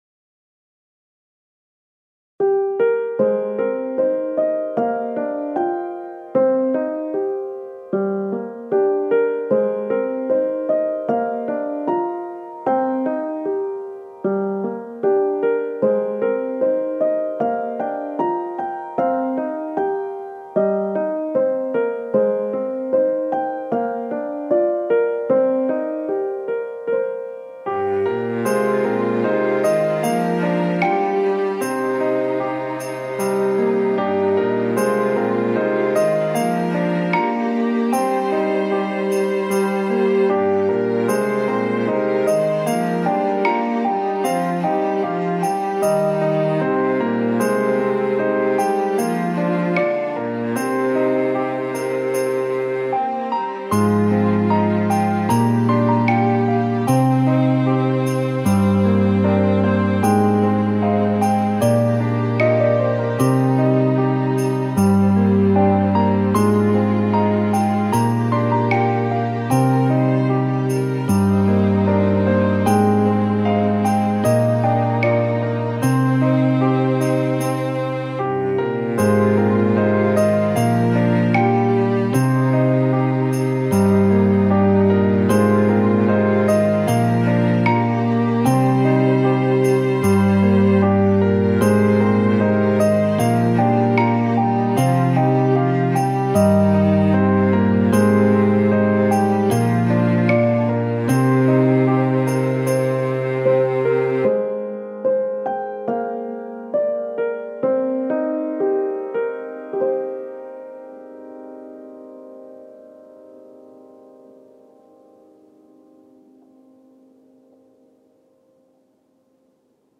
ロング暗い穏やか